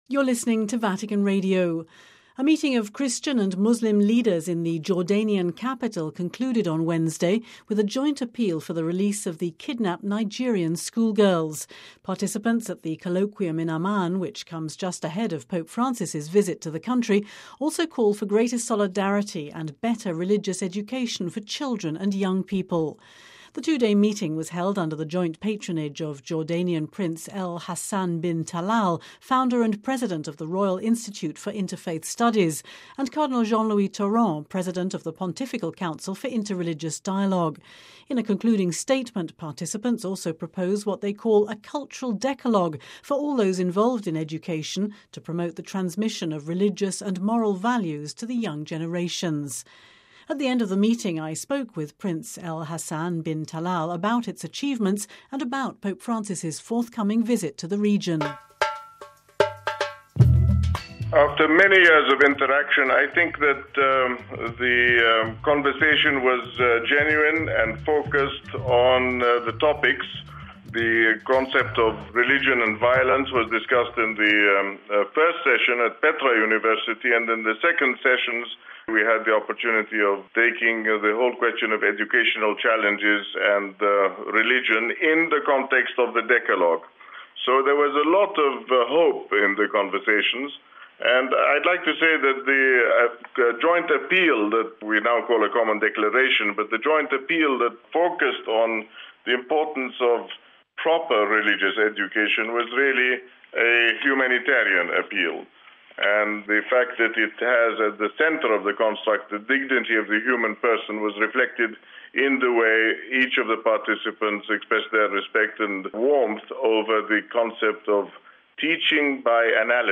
spoke with Prince El Hassan bin Talal about its achievements and about Pope Francis’ forthcoming visit to the region…